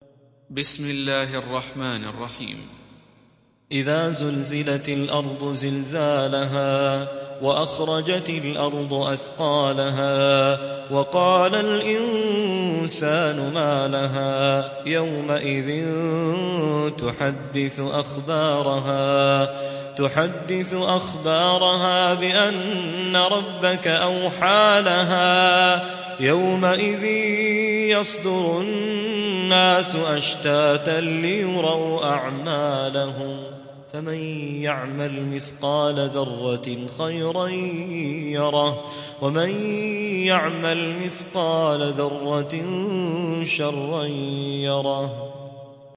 Hafs an Asim